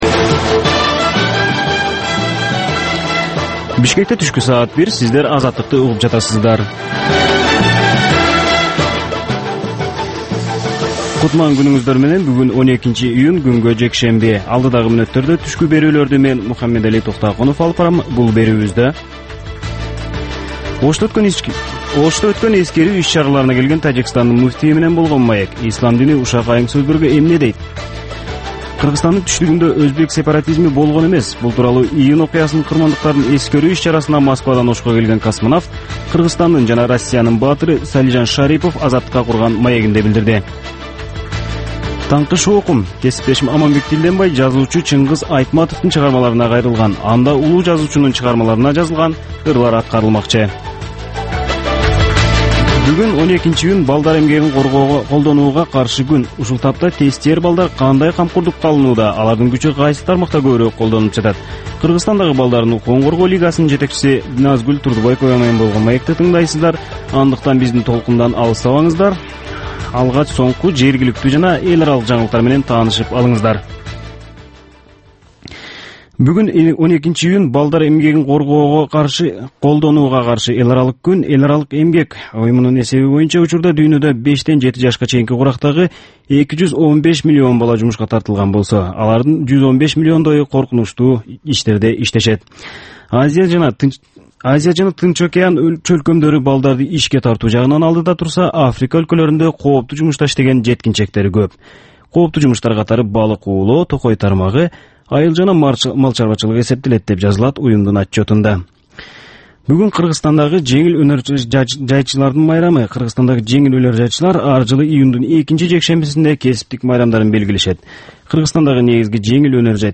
Түшкү саат 1деги кабарлар